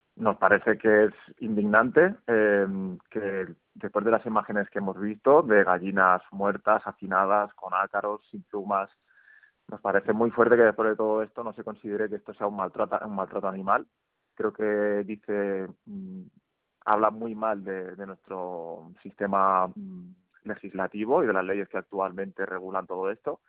Aquestes són declaracions a IB3 Ràdio: